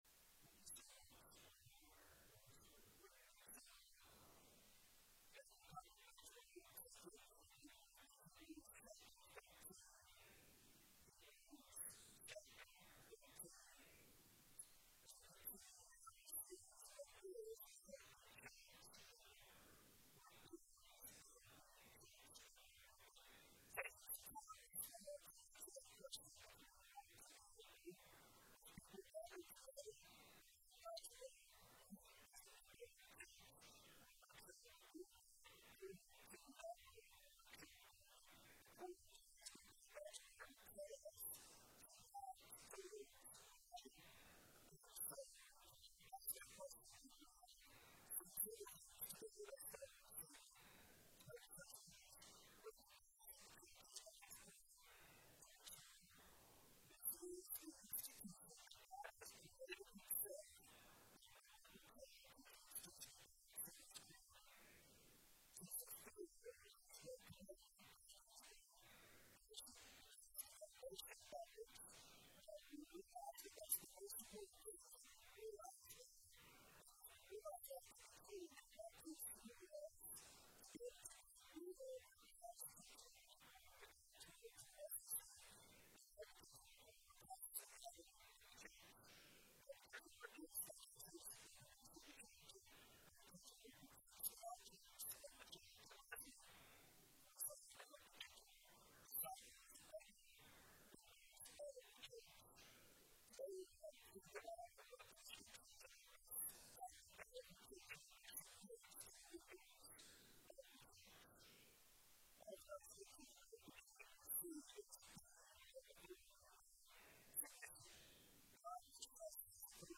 November 2, 2025 Sermon Audio.mp3